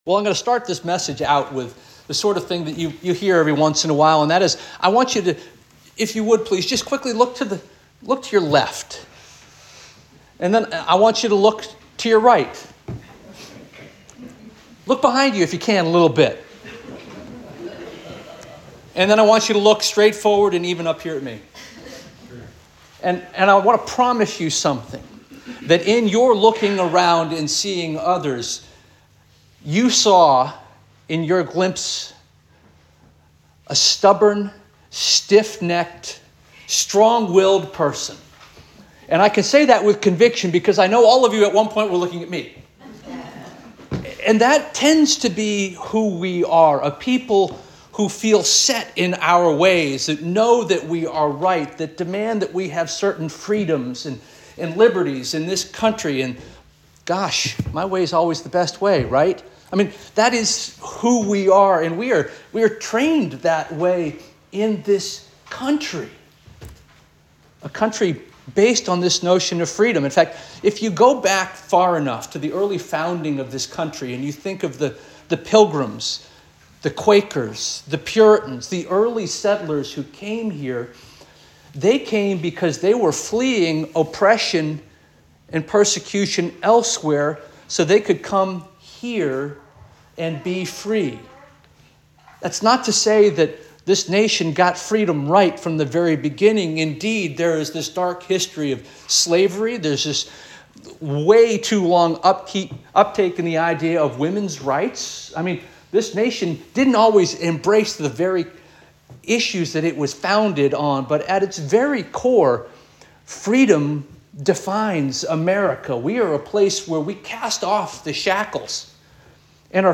February 23 2025 Sermon